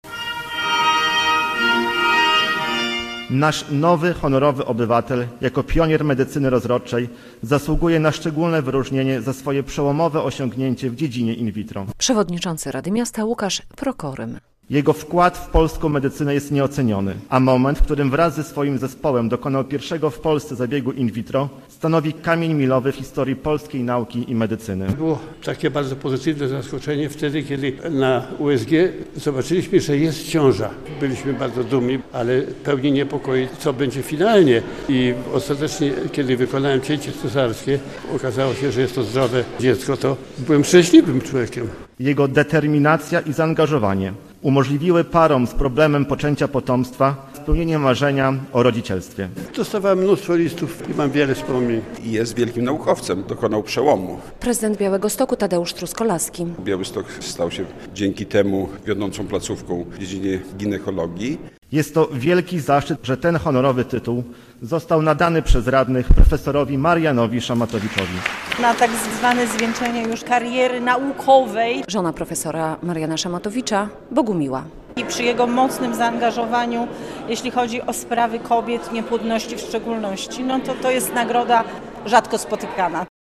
Radni zdecydowali o tym w połowie stycznia, a w sobotę (17.02) ten znany w kraju i za granicą ginekolog na uroczystej sesji Rady Miasta odebrał akt nadania tytułu.
relacja